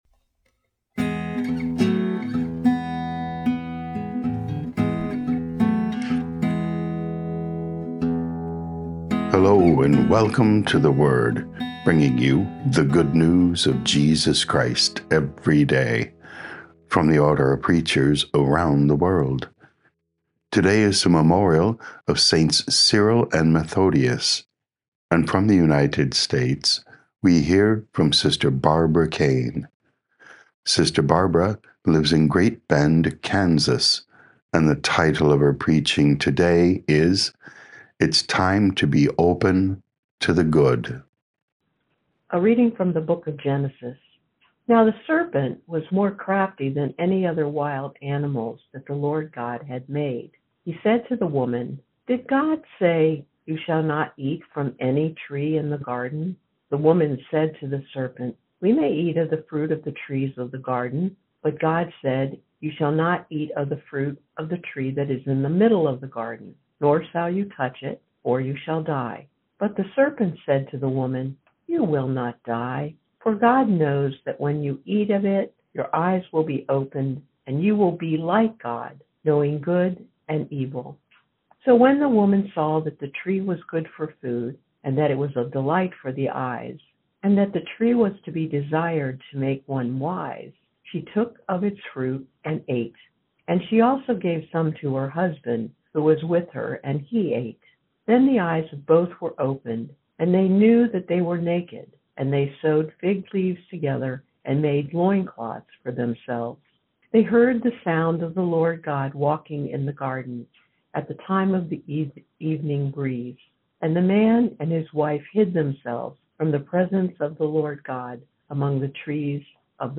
OP Preaching